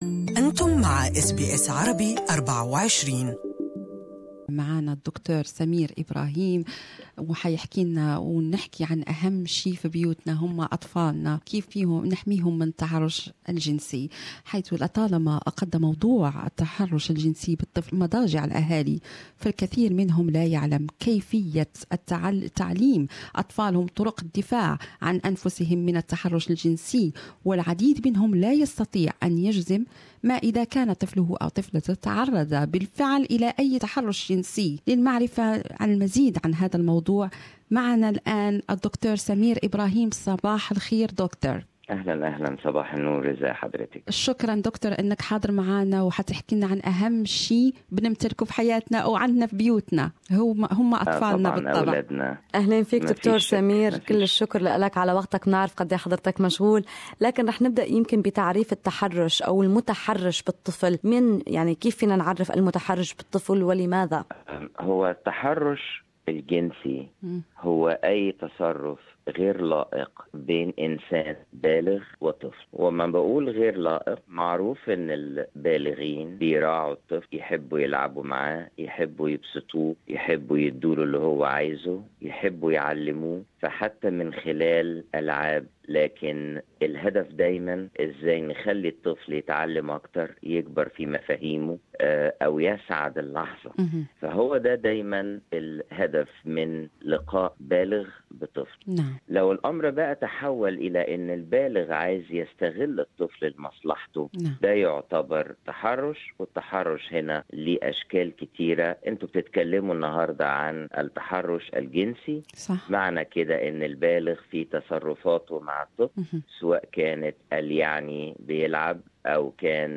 حوارا